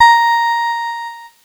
Cheese Note 16-A#3.wav